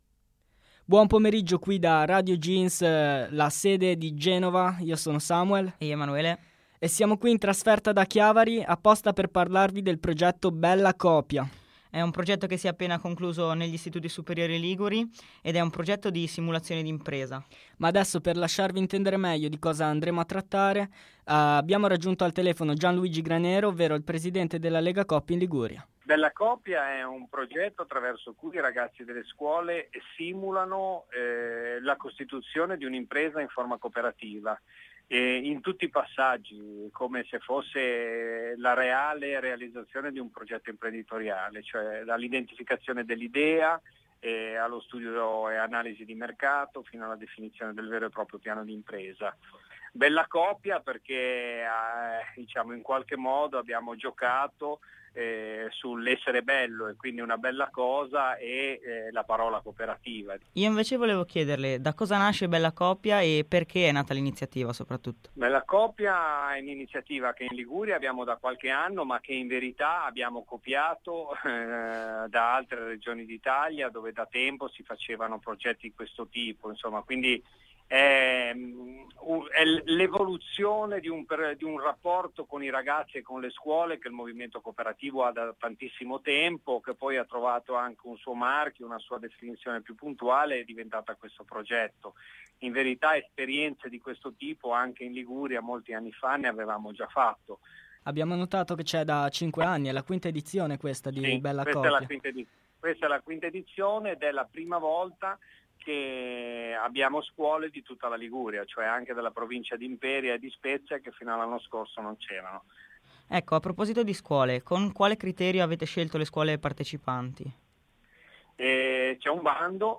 play_circle_filled Bella Coopia Radio Jeans Centro Giovani Chiavari Si è appena concluso in Liguria il progetto Bella Coopia. Intervista